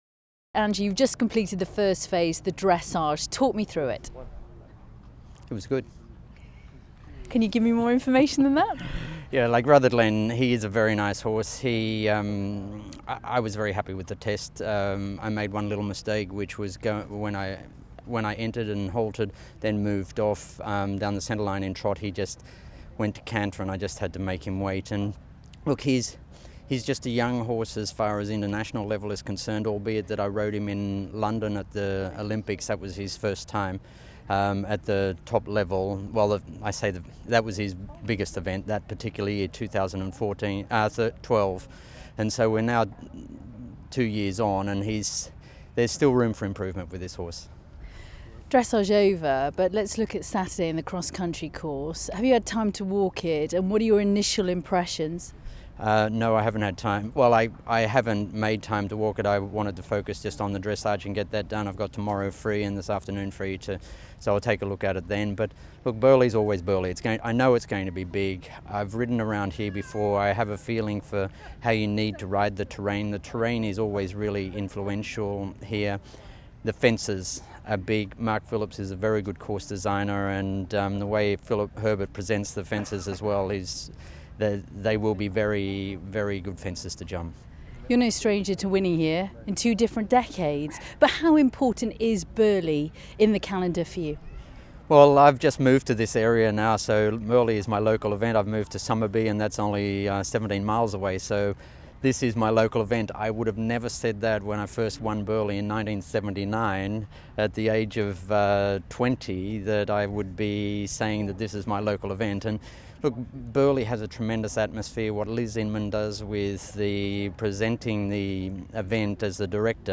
The interview is conducted by myself!